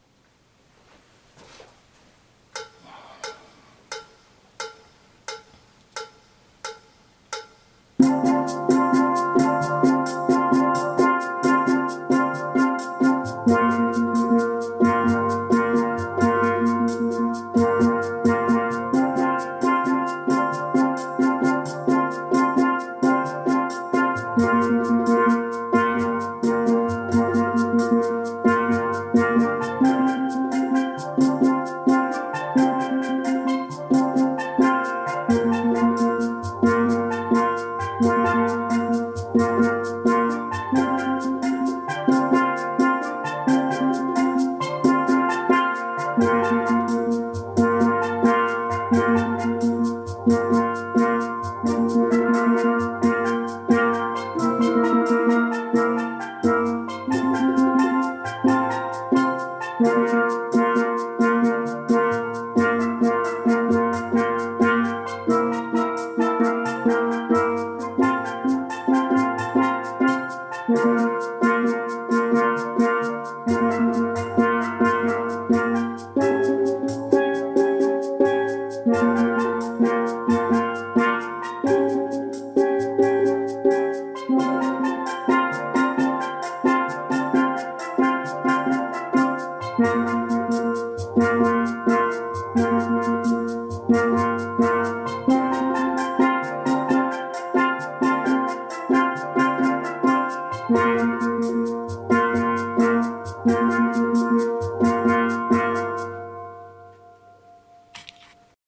Alto Maloya Vid .mp3